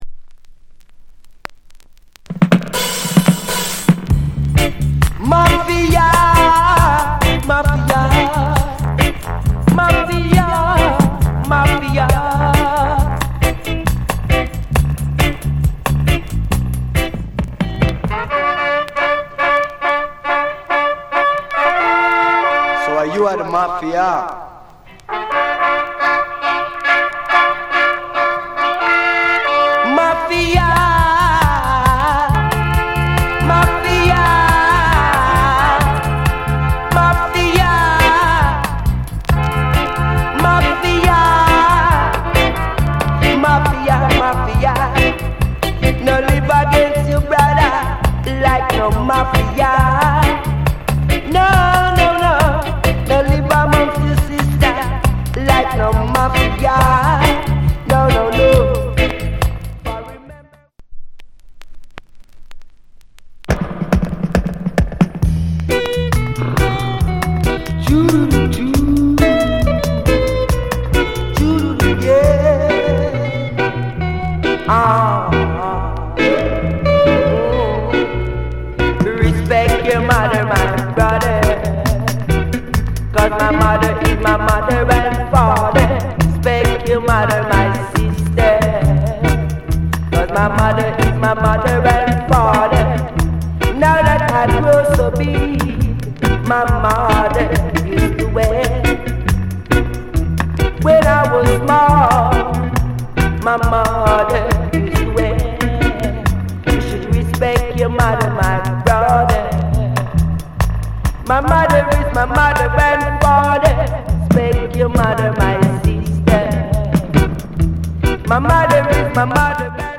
* 試聴 A-2, 4 B-1 ** 試聴 B-1傷による周期的なパチノイズ。